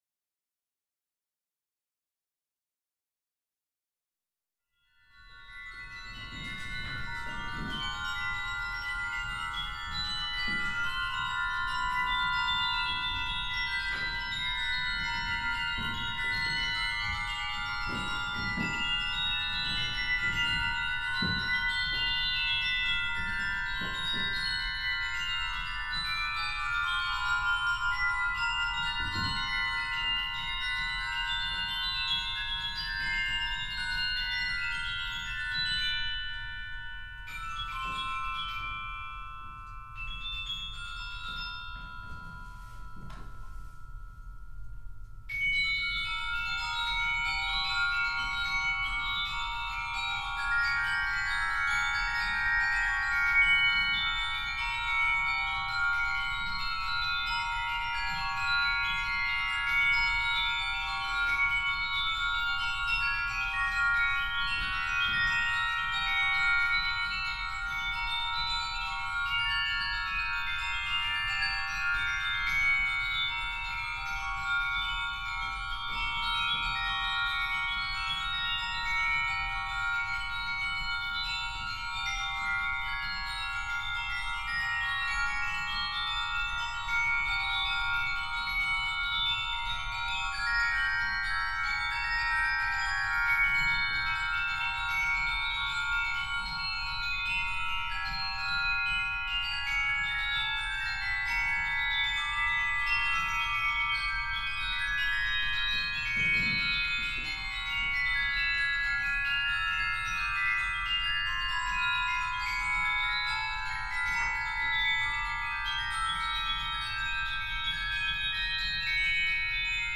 for 2 glockenspiels